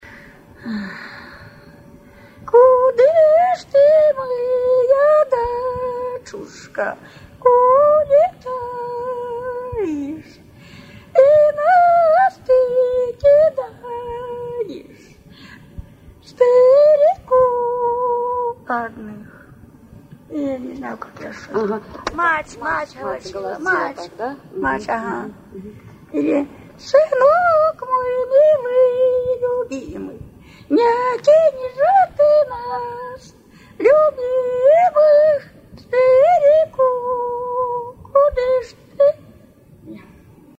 Свадебные обрядовые песни в традиции верховья Ловати
«Кудыш ты, моя дочушка, улетаешь» Голошение матери на свадьбе исп.